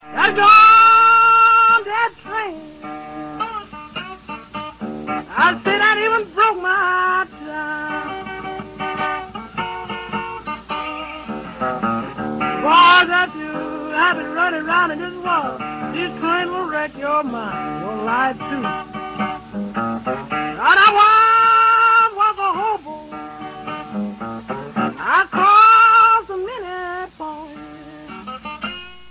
аккорды вообще не меняются.